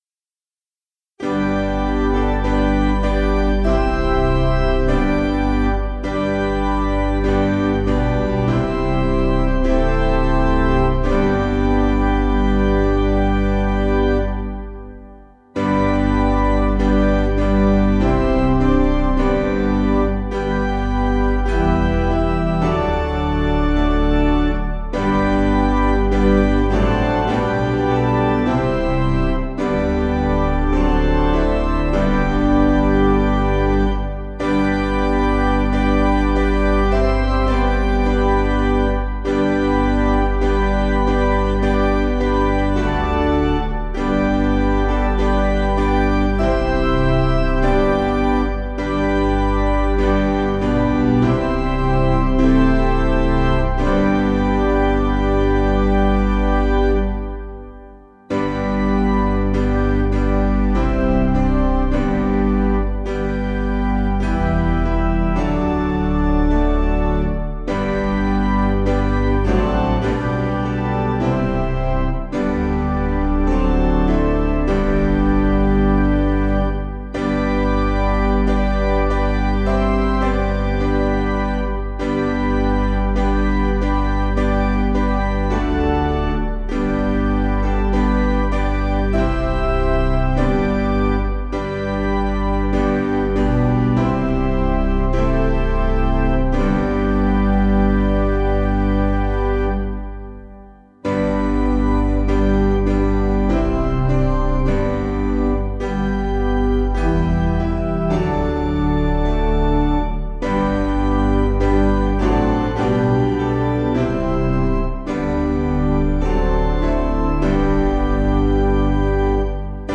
Basic Piano & Organ